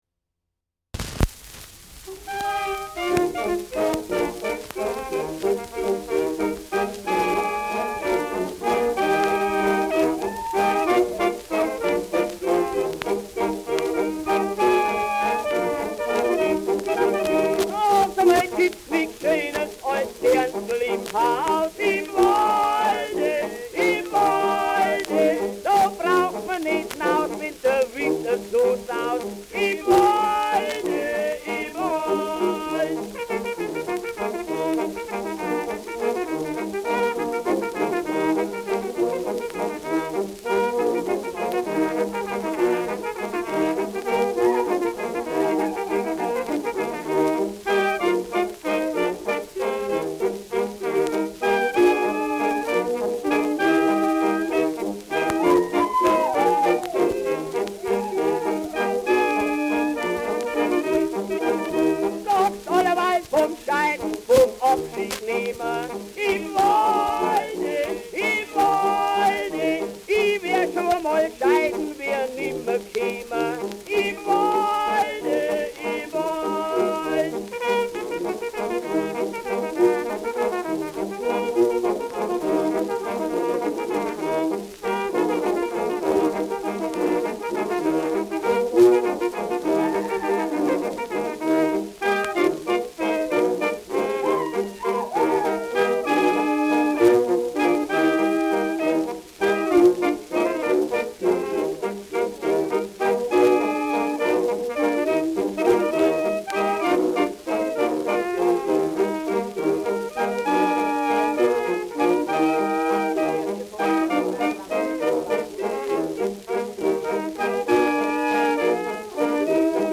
Im Wald : Walzer mit Gesang
Schellackplatte
Leichtes Leiern
Vierzeiler mit charakteristischem Einwurf „Im Walde, im Walde!“